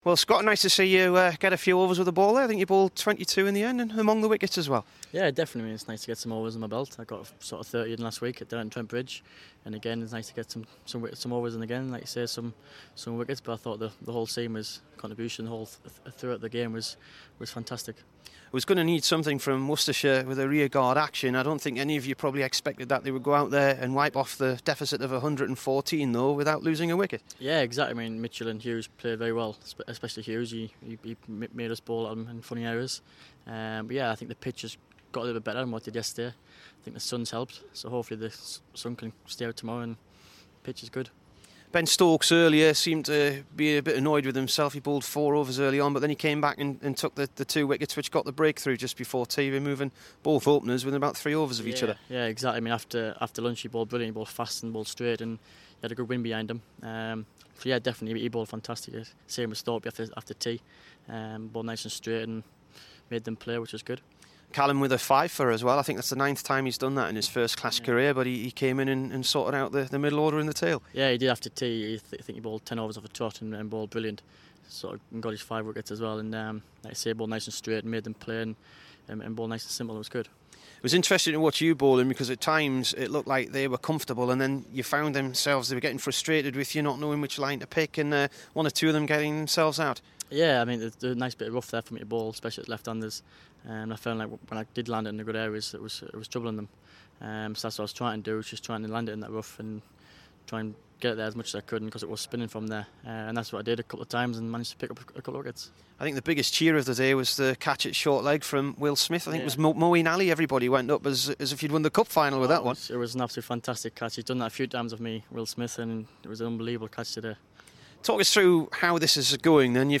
SCOTT BORTHWICK INT